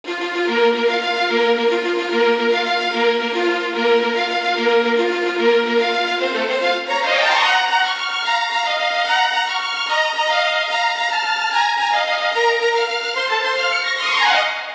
No additional processing.